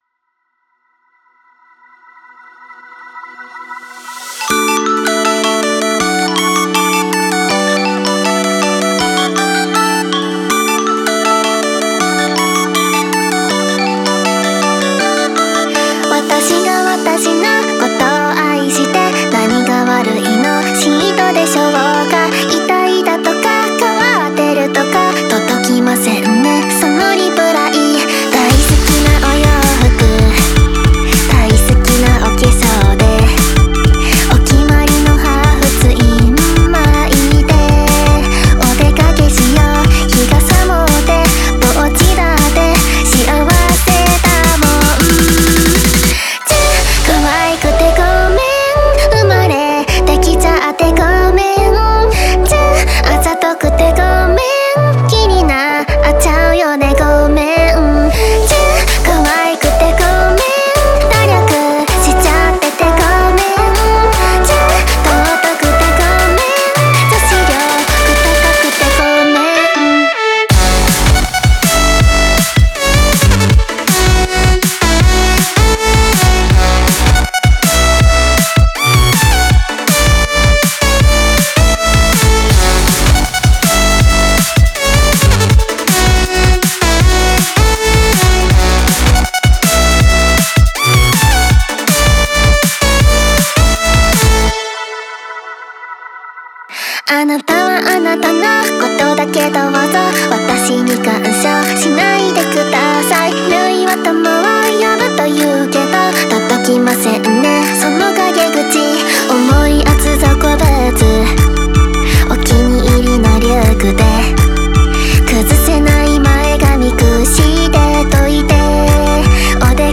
EDM(ダンスミュージック)というジャンルの曲なのですが、YouTubeやこのブログでも紹介しています。